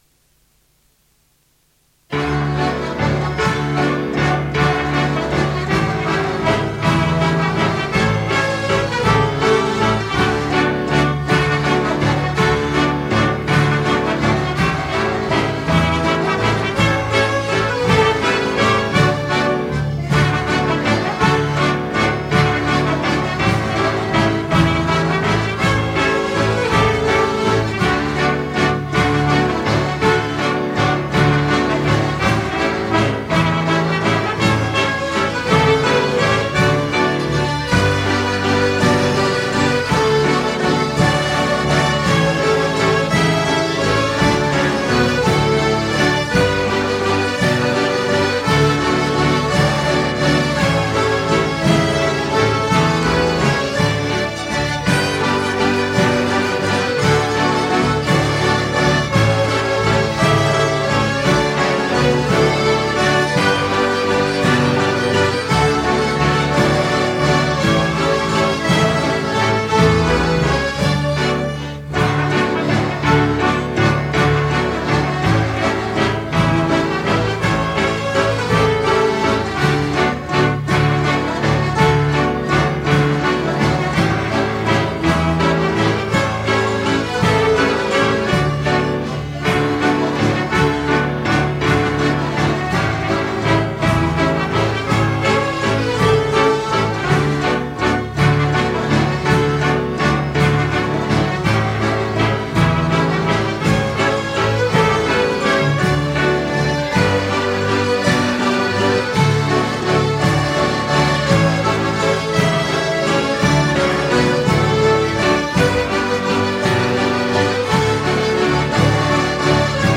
Kassettebånd med Himmerlandsorkestret